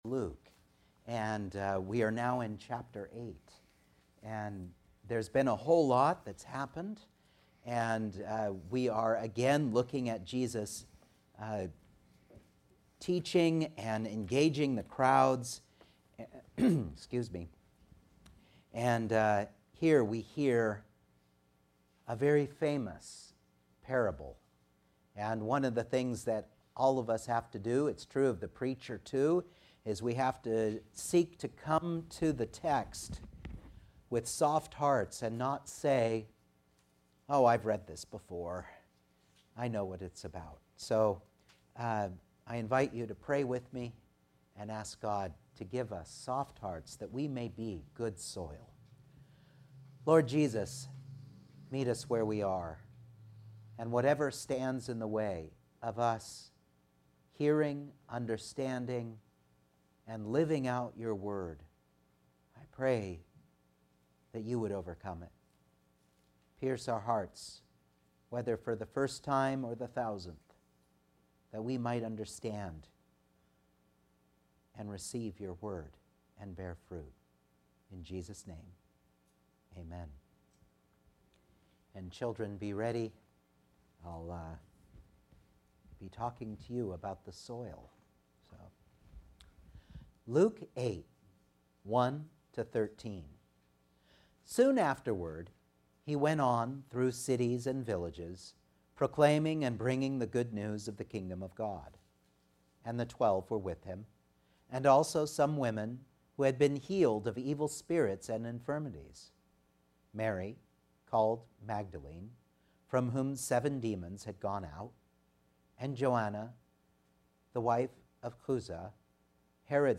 Luke 8:1-13 Service Type: Sunday Morning Outline